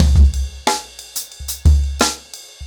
InDaHouse-90BPM.17.wav